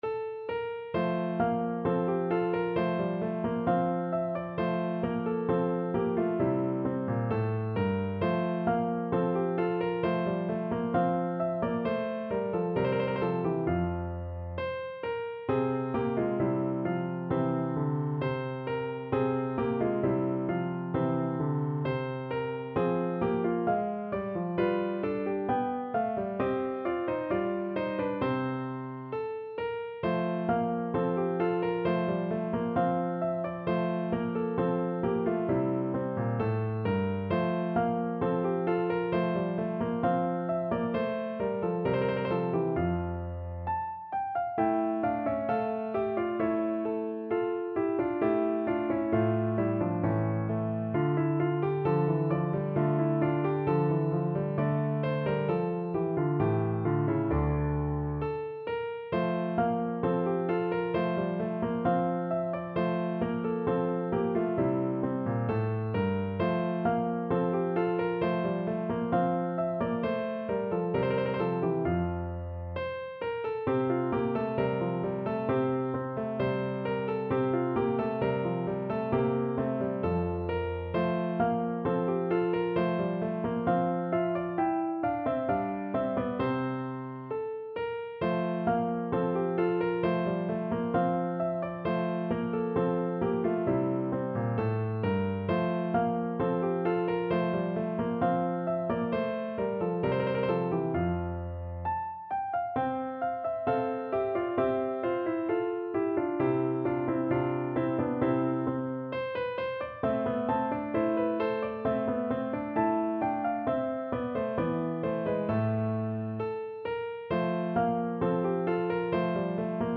Piano version
No parts available for this pieces as it is for solo piano.
2/2 (View more 2/2 Music)
~ = 100 Allegretto =c.66
Piano  (View more Intermediate Piano Music)
Classical (View more Classical Piano Music)